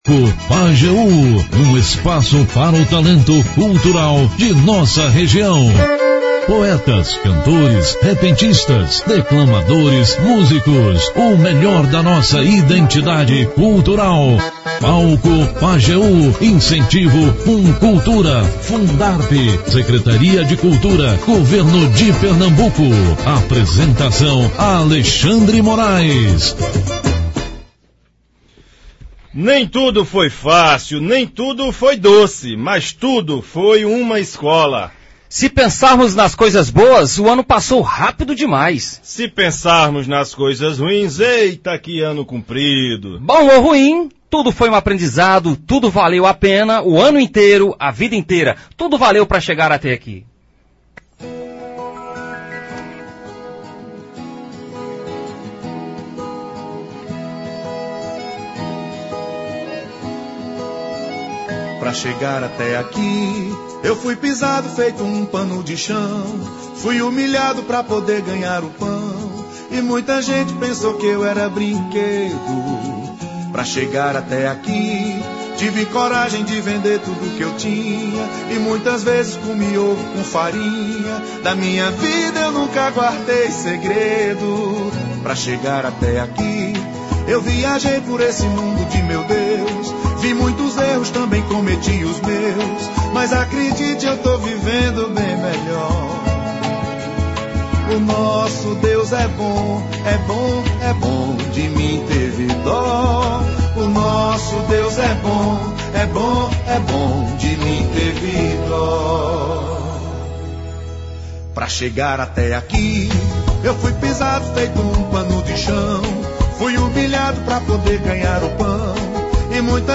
Os dois abrilhantaram o último programa do ano com muita música de qualidade, belas histórias e um show de cultura.